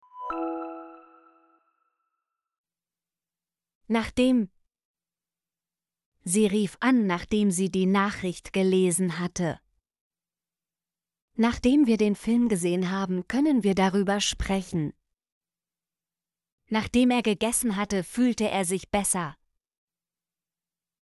nachdem - Example Sentences & Pronunciation, German Frequency List